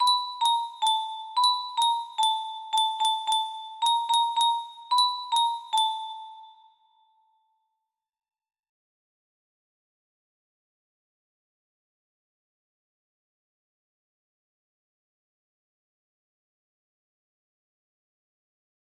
Hot crossed buns music box melody